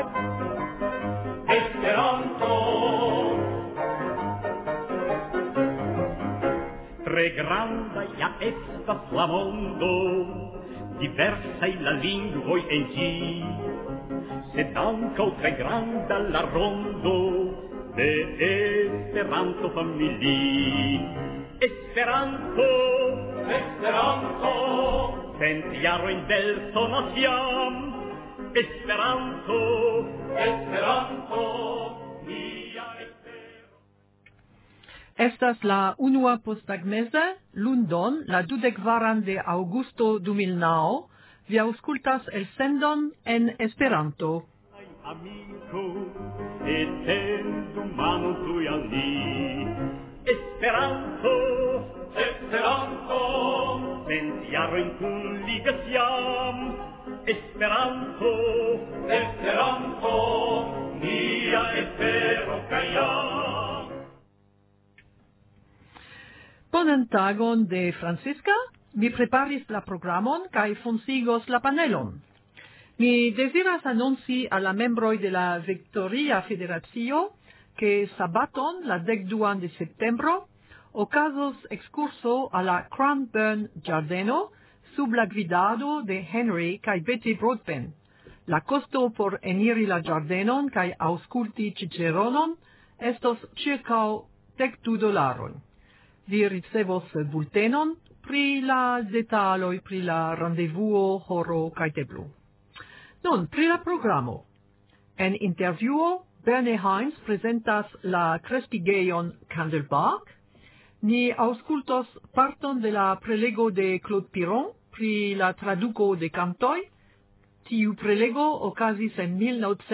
Intervjuo